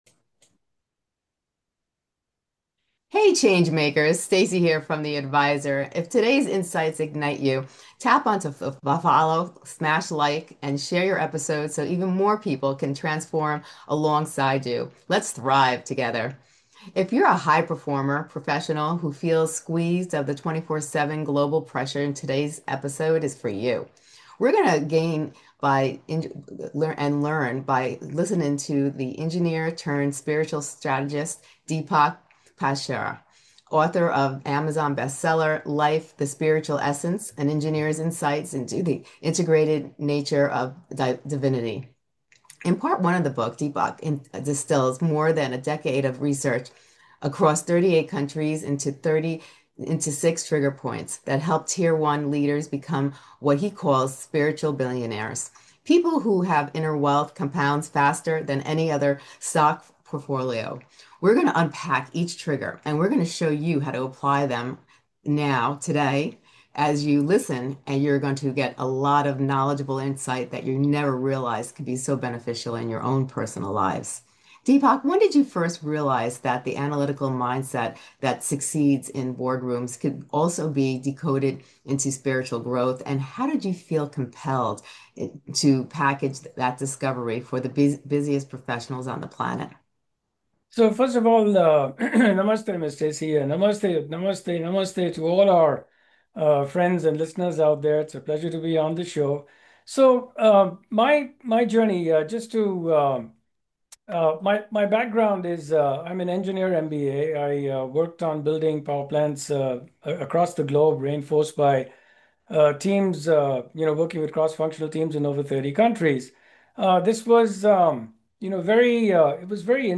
Love insightful and life-changing interviews?